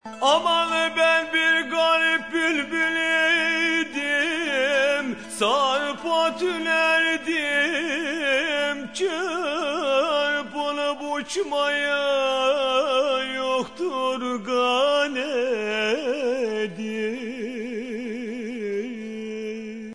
土耳其民謠２
專輯中一位聲音渾厚的男性演唱土耳其歌謠，演唱者以自然發聲法演唱，運用胸腔共鳴，唱長音時震動喉嚨，略帶抖音，以聲音展現豐富的感情，非常具有民俗風特色，但其所唱的歌詞內容我們不甚了解，只能透過簡介臆測，有點像鴨子聽雷！
不過，對於專輯中絃鳴樂器與彈撥樂器及擊鼓節奏，我們可是大感興趣。